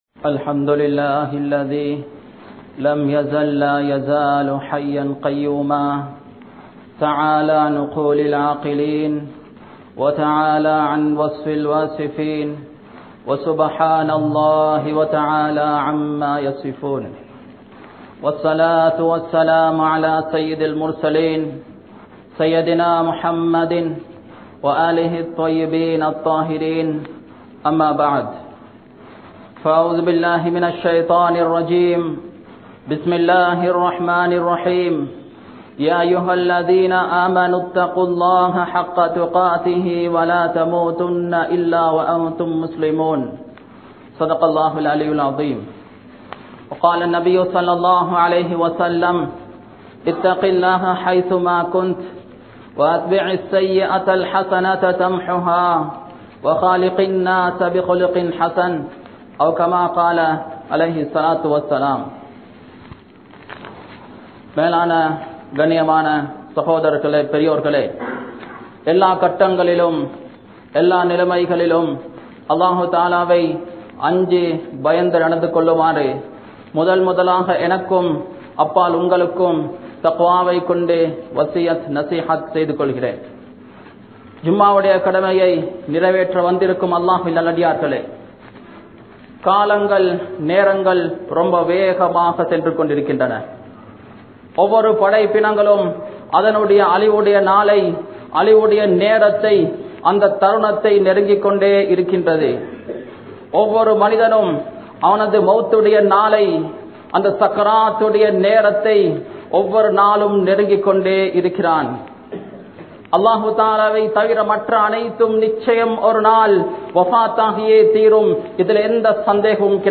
Marukkamudiyatha Maranam (மறுக்க முடியாத மரணம்) | Audio Bayans | All Ceylon Muslim Youth Community | Addalaichenai
Town Jumua Masjidh